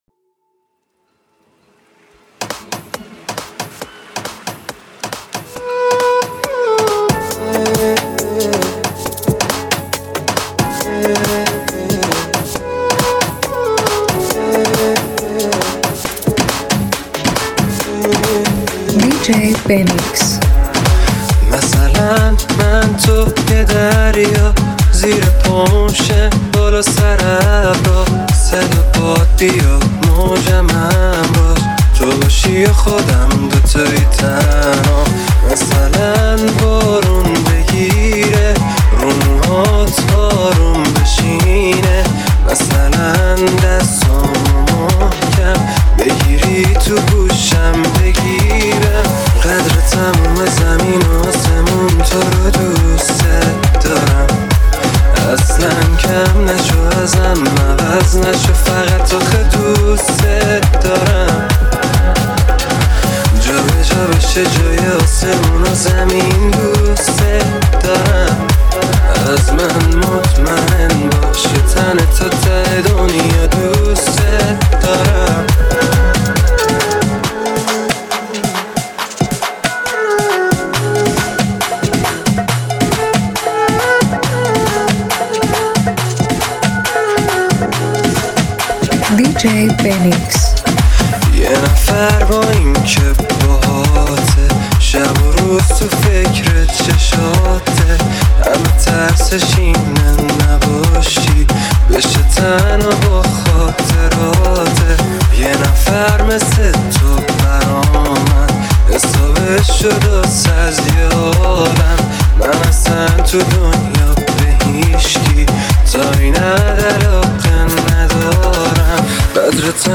این ریمیکس پرانرژی و جذاب
صدای گرم